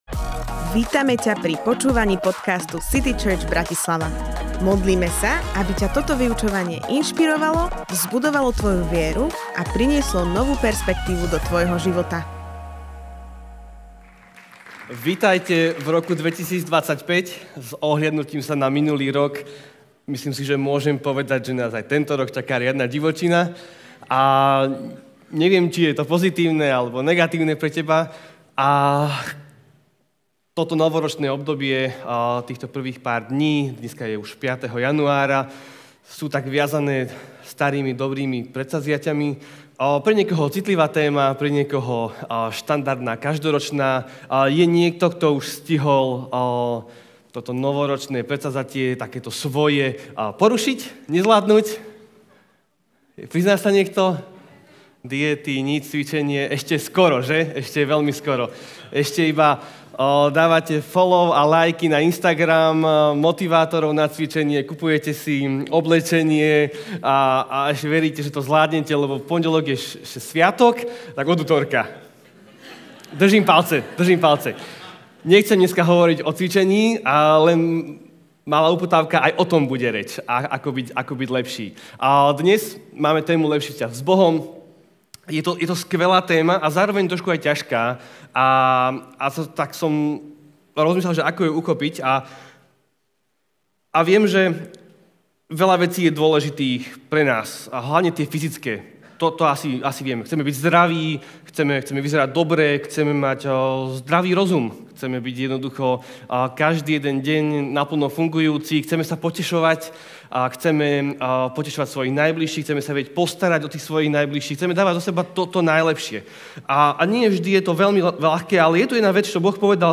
Lepší vzťah s Bohom Kázeň týždňa Zo série kázní